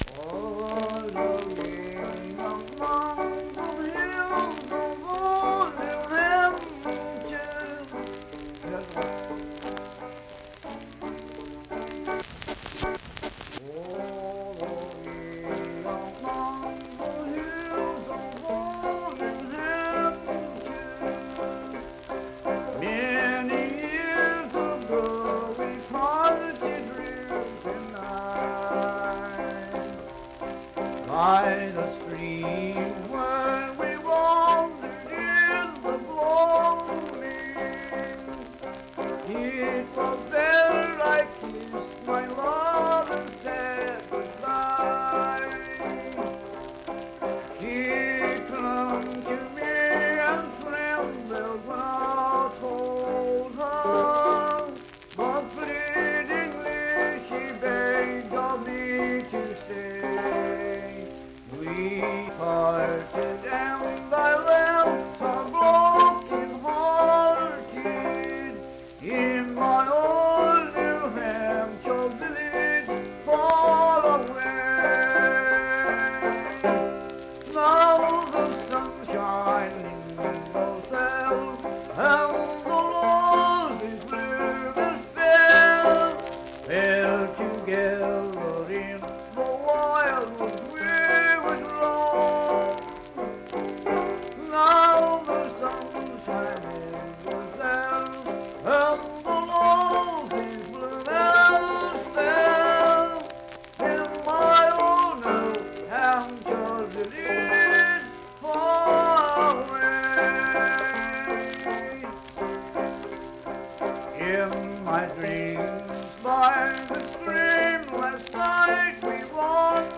Banjo accompanied vocal music performance
Verse-refrain 2(8w/R) & R(4).
Banjo, Voice (sung)